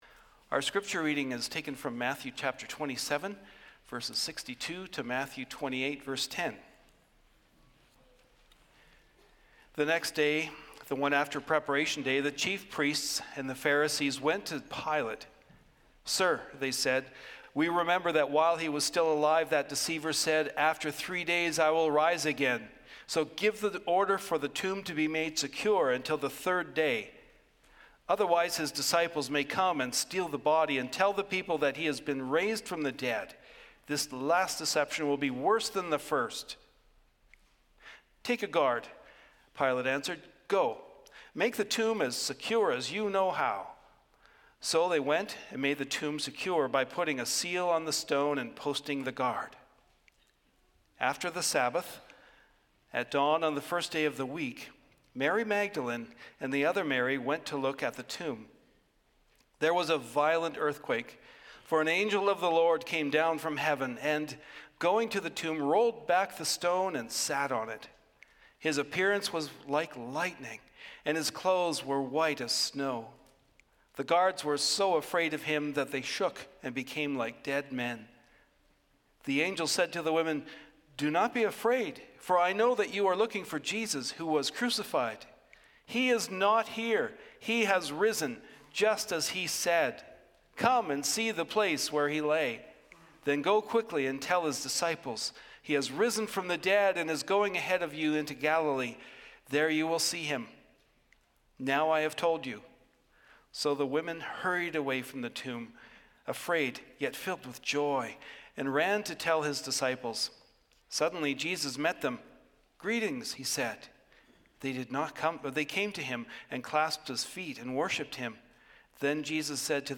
Sermons | Community Christian Reformed Church
Easter Sunday